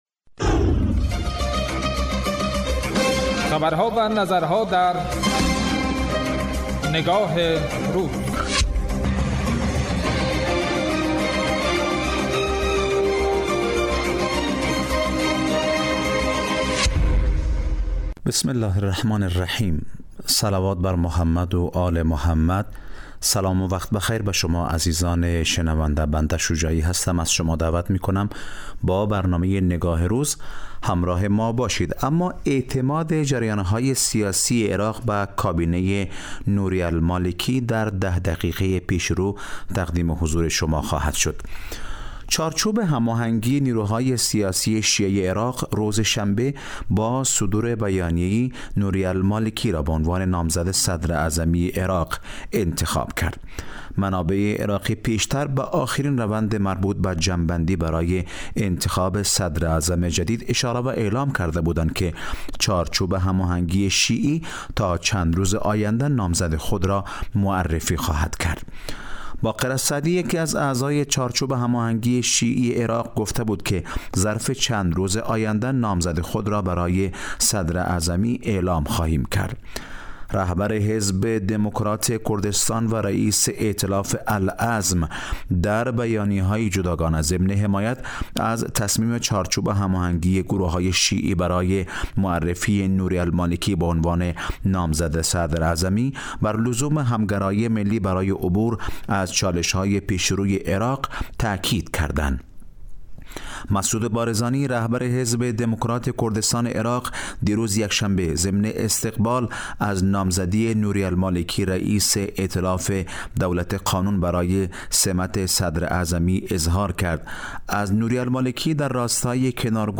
برنامه تحلیلی نگاه روز از شنبه تا پنجشنبه راس ساعت 14 به مدت 10 دقیقه پخش می گردد